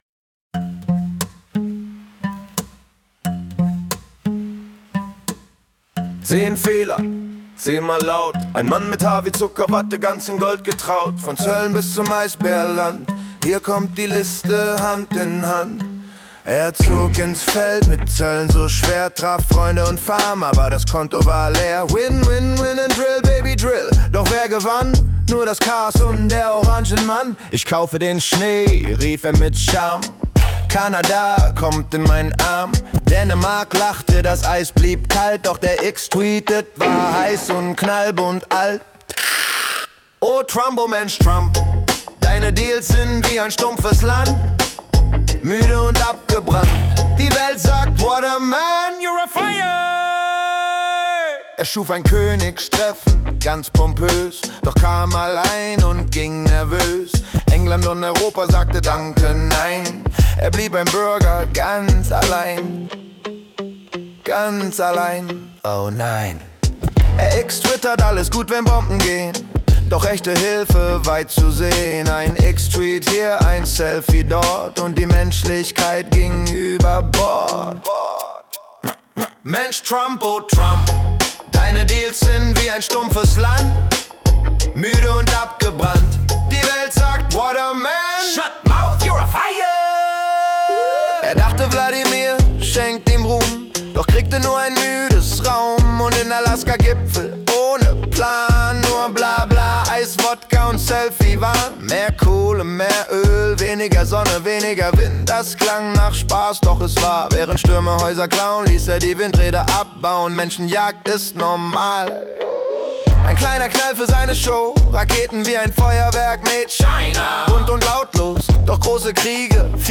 Es ist ein musikalisches Kabarett über Machtgier, Eitelkeit und laute Sprüche, das gleichzeitig zum Lachen und Kopfschütteln einlädt.